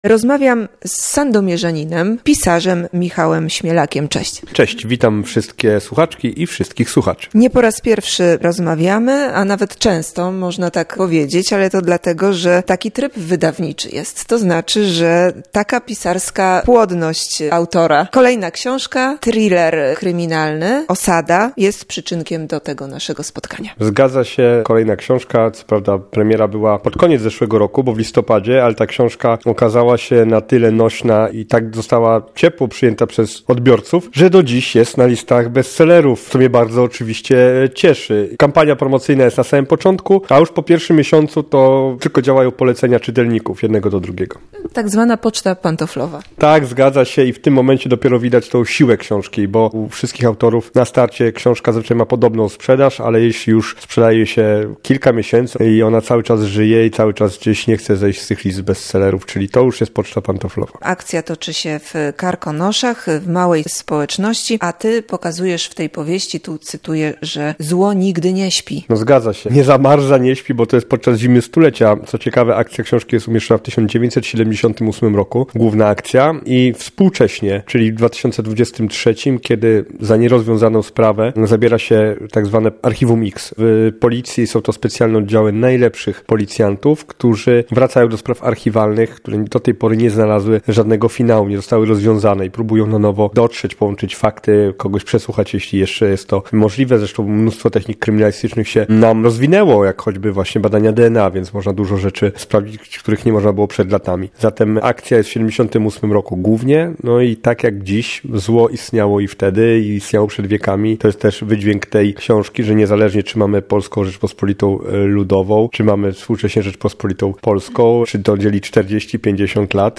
Rozmowa